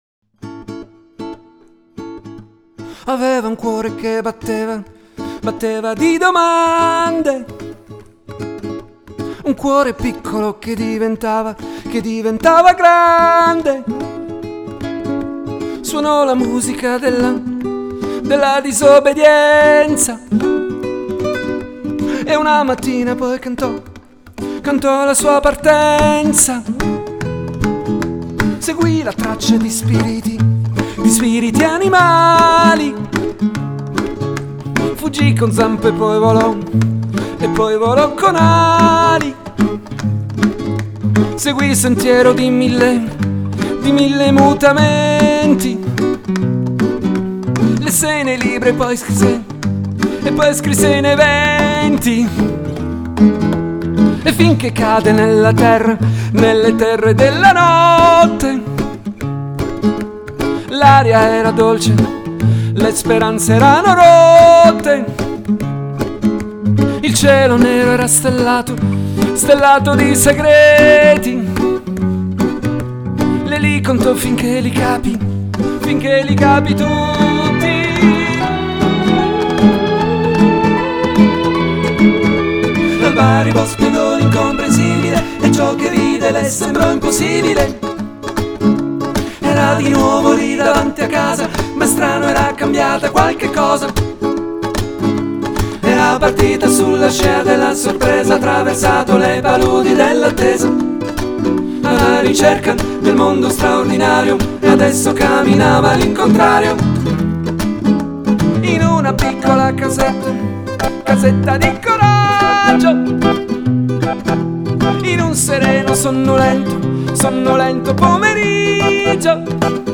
nella versione reggae in draft